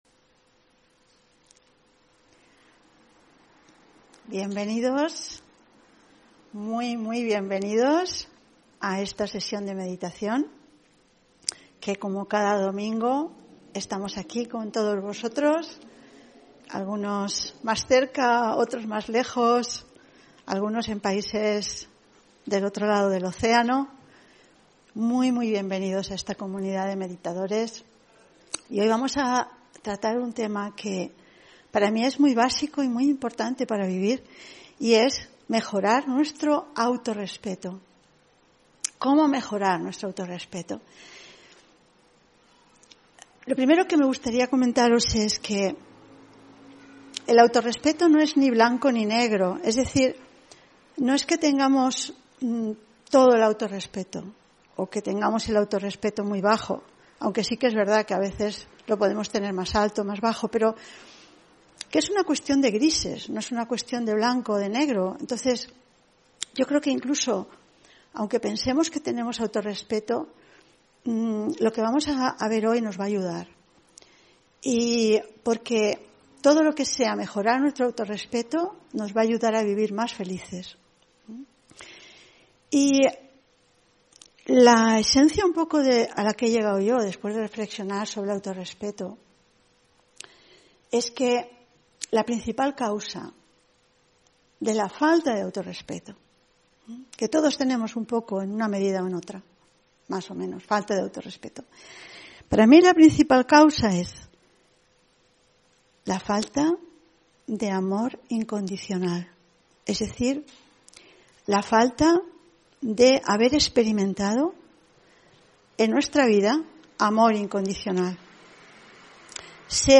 Meditación y conferencia: Naciste para ser feliz (26 Abril 2023)